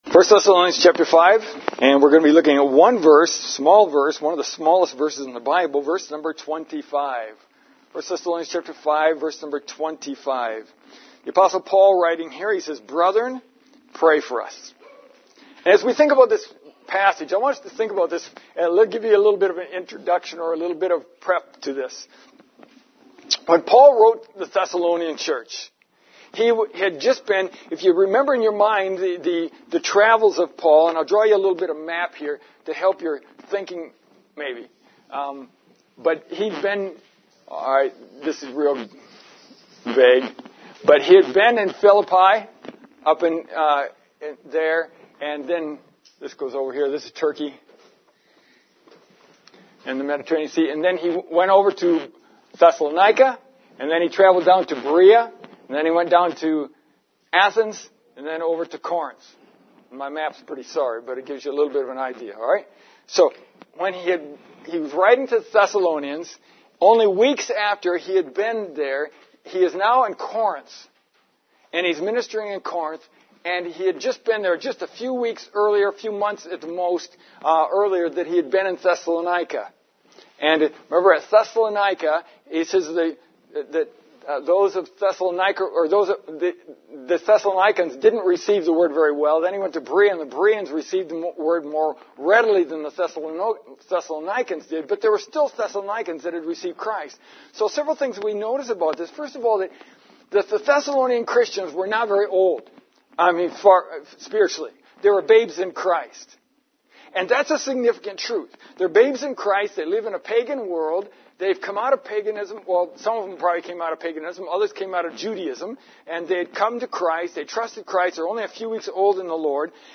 It is our duty to pray for others. This evening I want us to consider five needs that your preacher and missionaries have that you ought to pray for daily.